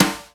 Index of /90_sSampleCDs/300 Drum Machines/Akai MPC-500/1. Kits/Garage Kit
grg brush snare2.WAV